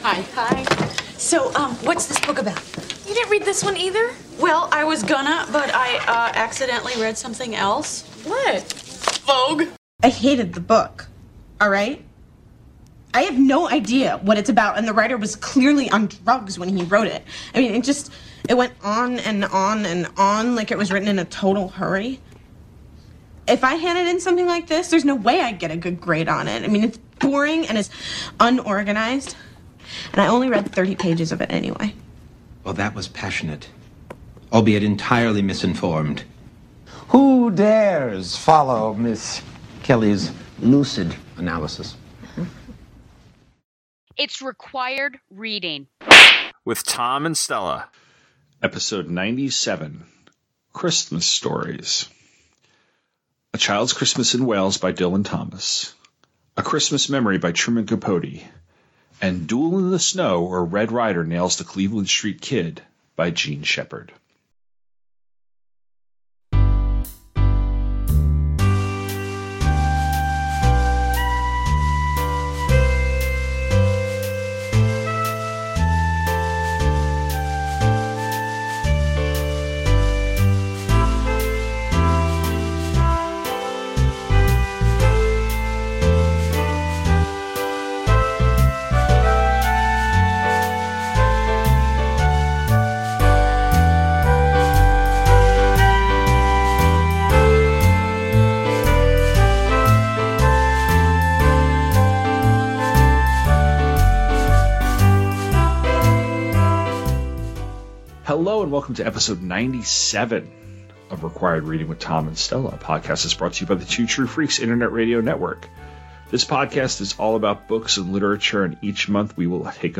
is two teachers talking about literature. Each episode, we will be taking a look at a single work, analyzing it, criticizing it and deciding if it’s required reading.